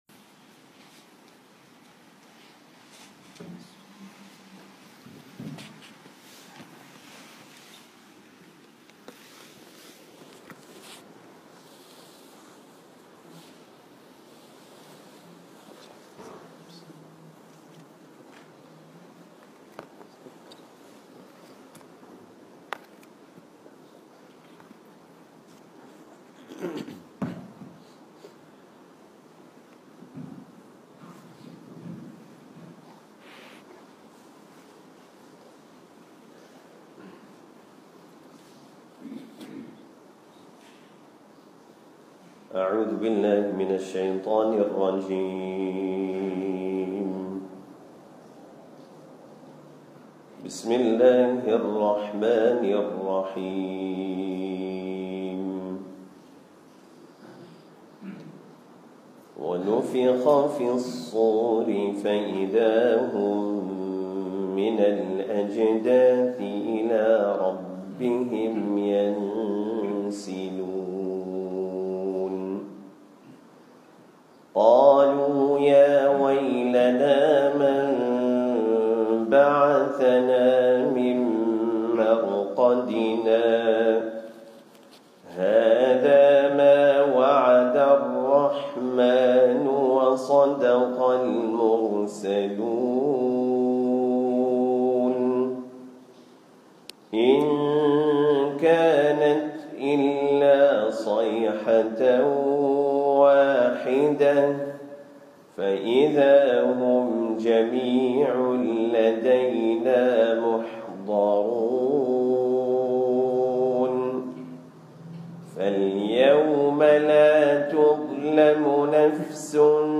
Recent Lectures
tafseer-surah-yaseen-7-feb.mp3